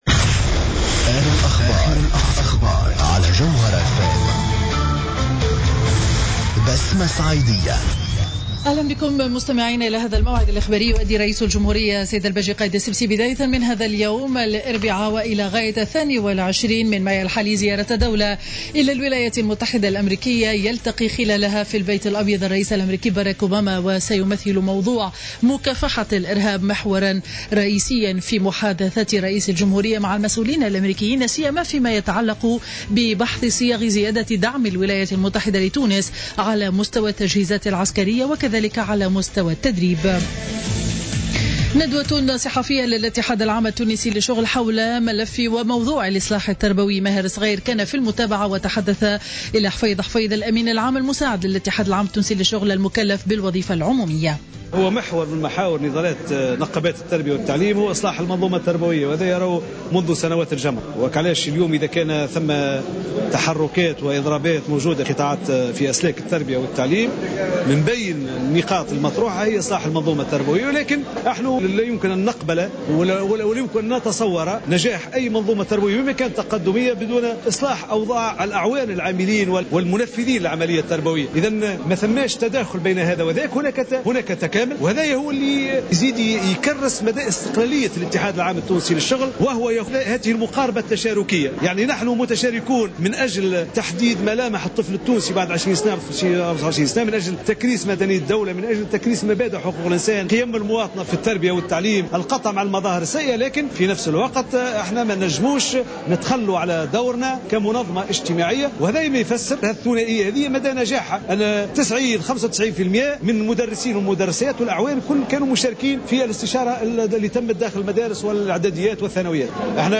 نشرة أخبار منتصف النهار ليوم الإربعاء 20 ماي 2015